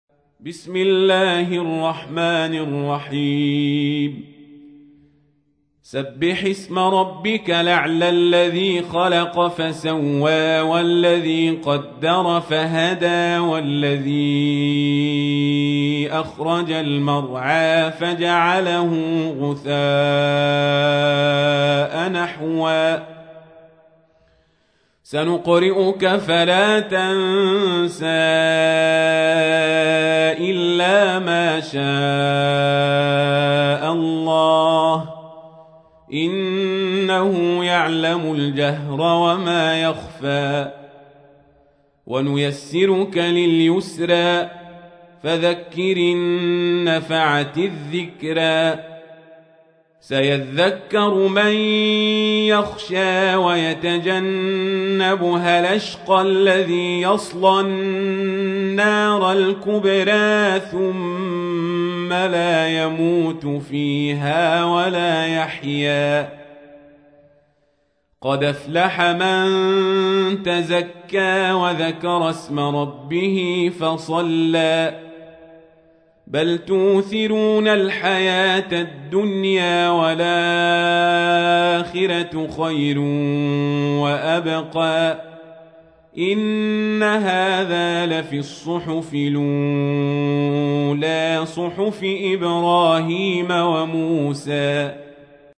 تحميل : 87. سورة الأعلى / القارئ القزابري / القرآن الكريم / موقع يا حسين